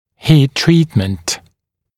[hiːt-‘triːtmənt][хи:т-‘три:тмэнт]термическая обработка